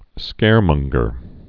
(skârmŭnggər, -mŏng-)